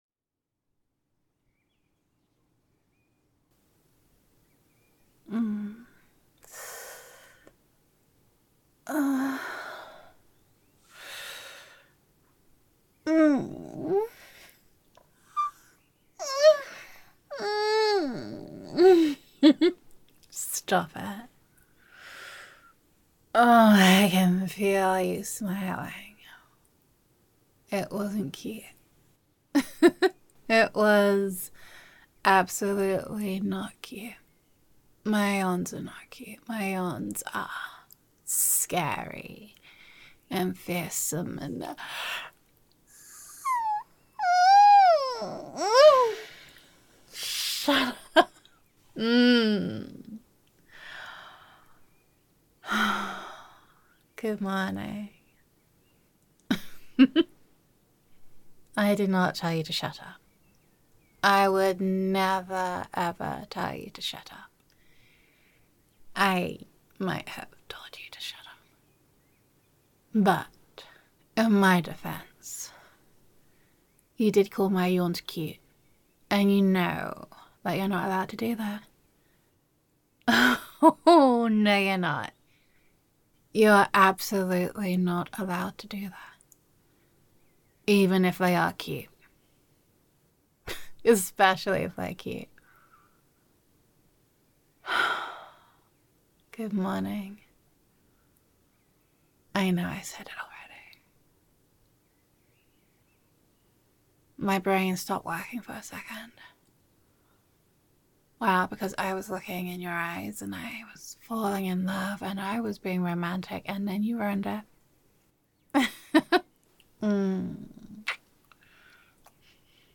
[F4A] Spoiled [Girlfriend Roleplay][Domestic][Cuddlesome][Adorable Yawns][Which Are Not Cute][Playful][Waking Up Together][Affectionate][Gender Neutral][Your Girlfriend Loves Waking Up in Your Arms]